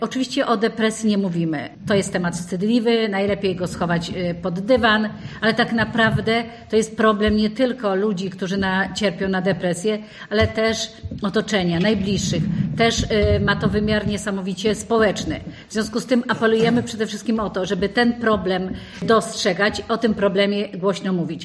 Czasami chorobę trudno jest zauważyć mówiła podczas konferencji prasowej członkini zarządu województwa mazowieckiego – Elżbieta Lanc: